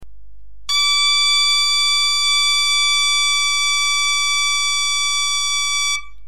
Bombarde
La 2ème octave : du Do octave au Si octave